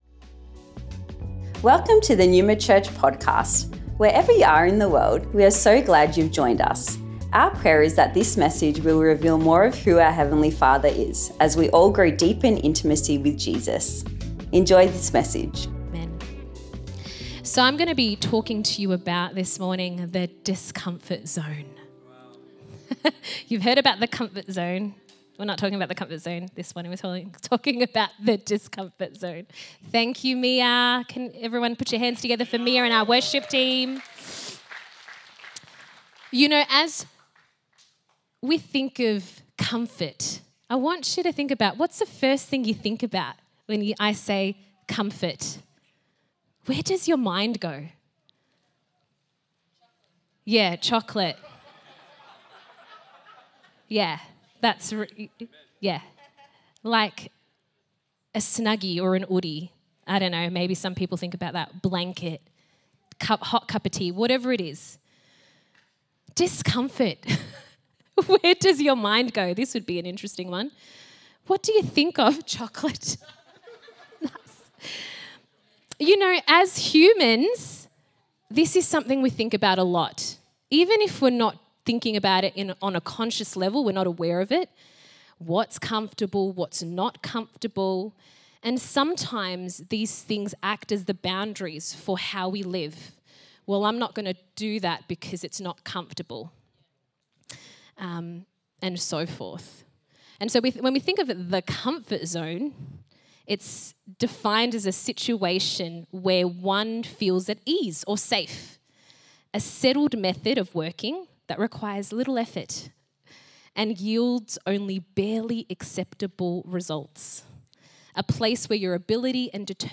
Originally recorded at Neuma Melbourne West on the 25th Feb 2025